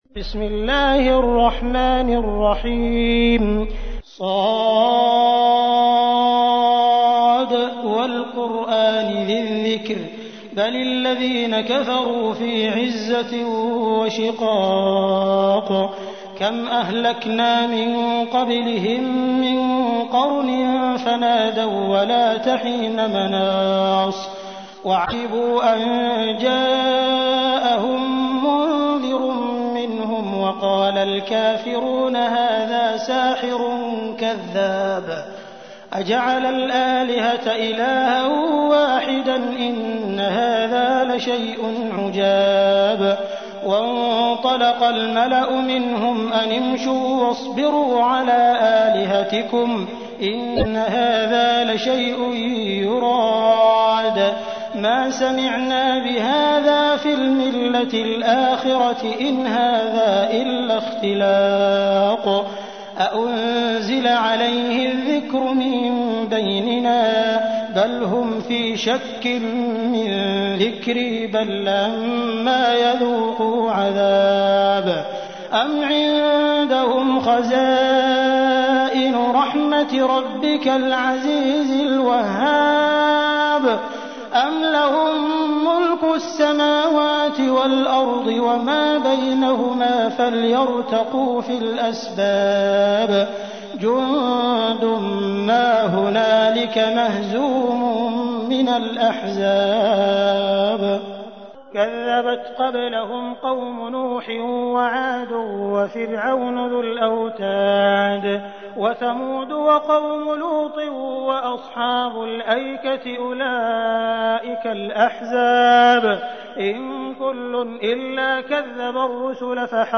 تحميل : 38. سورة ص / القارئ عبد الرحمن السديس / القرآن الكريم / موقع يا حسين